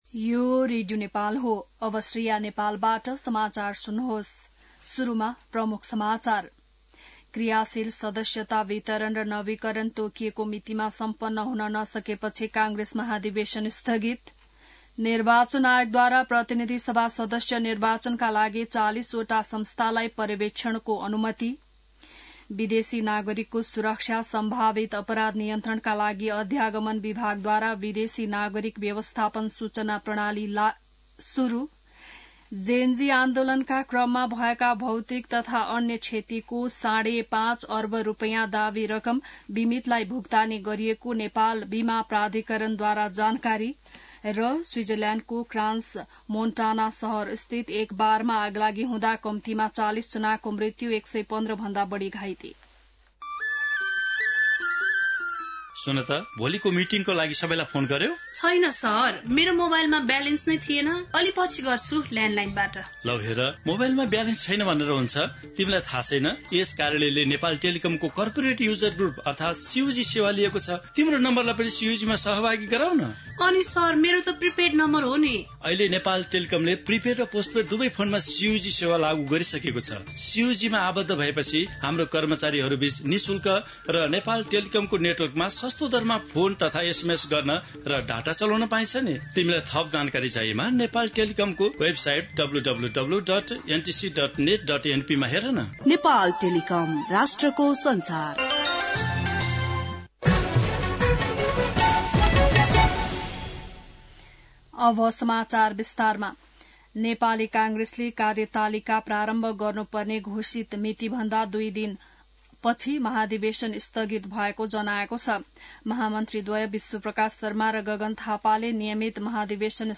An online outlet of Nepal's national radio broadcaster
बिहान ७ बजेको नेपाली समाचार : १८ पुष , २०८२